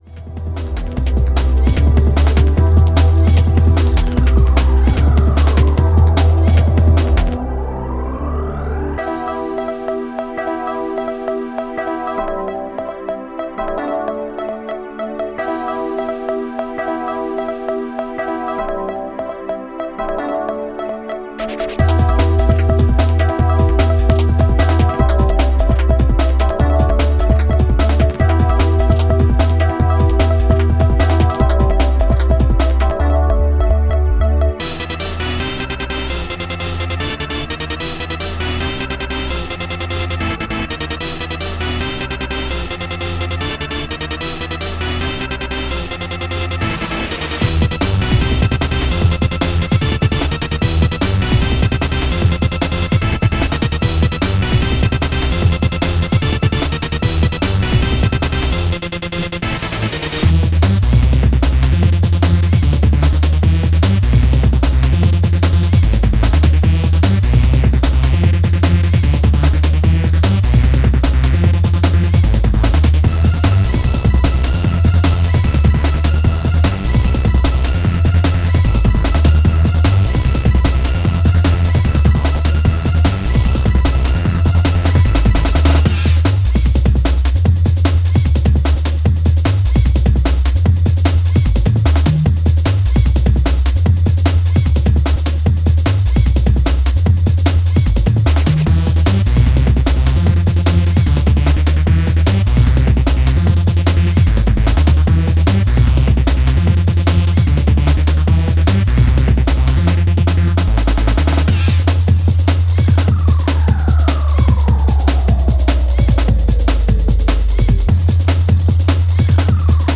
live PA performance